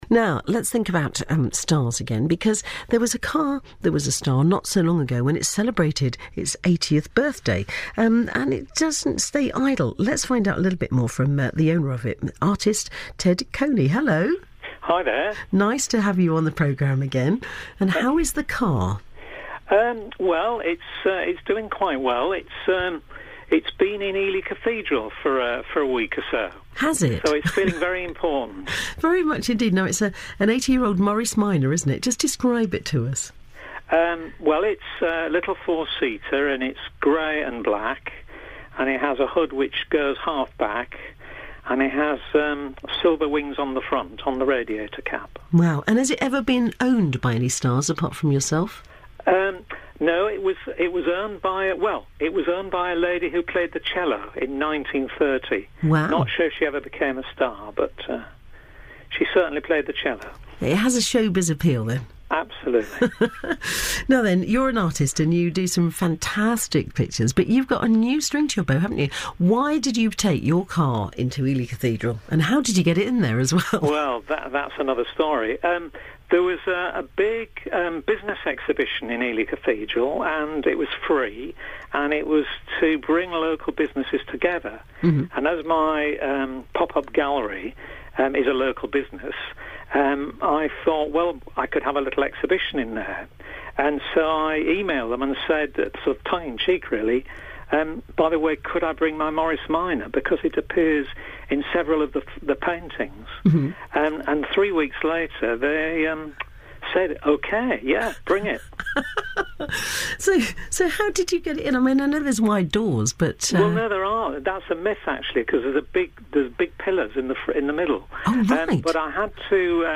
BBC Radio Cambridgeshire Interview 2012